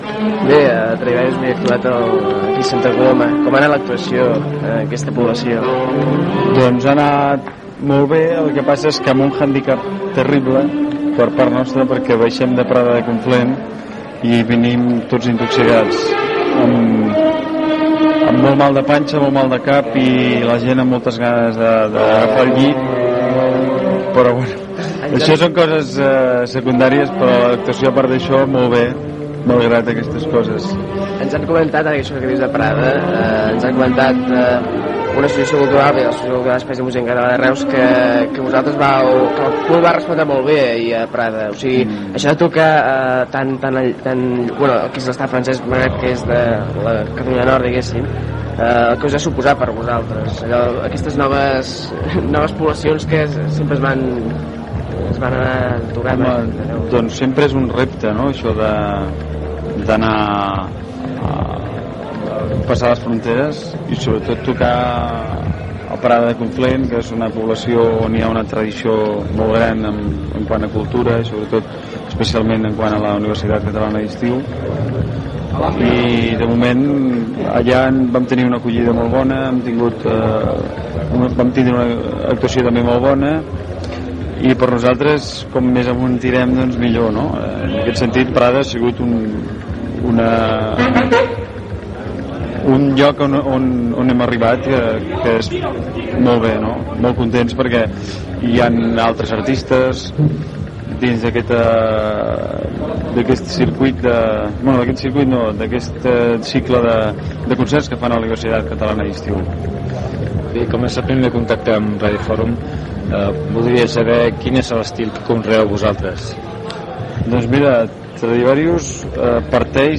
Entrevista a un dels integrants de Tradivarius que havia actuat a la Plaça del Castell de Santa Coloma de Queralt
Musical